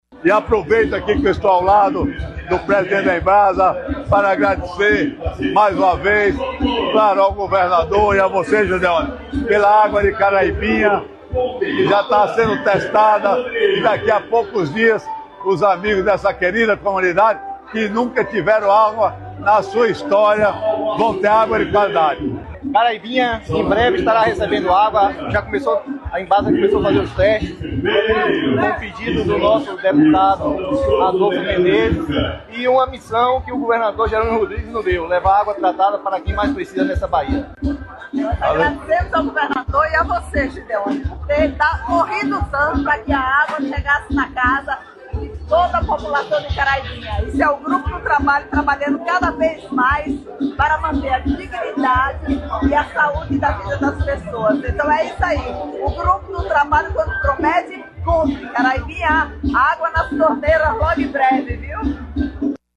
Sonora: Deputado estadual, Adolfo Menezes – Teste de água para Caraibinha